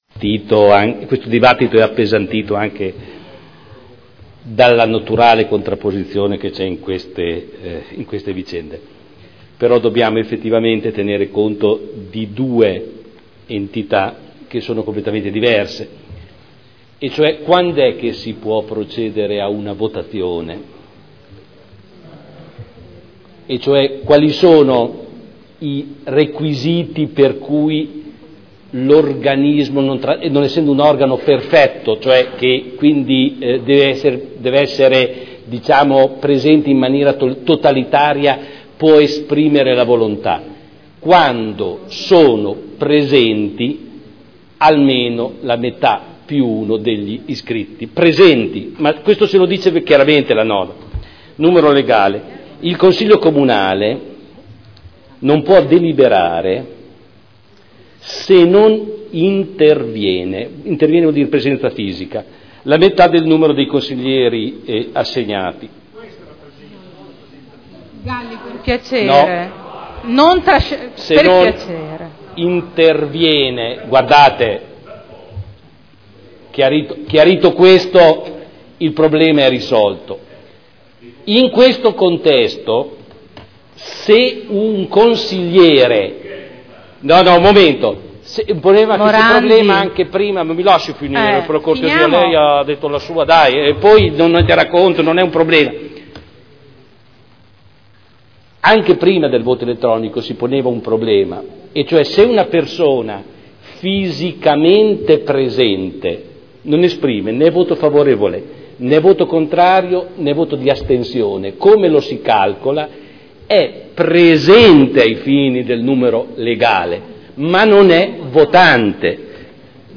Audio Consiglio Comunale
Seduta del 12 settembre 2011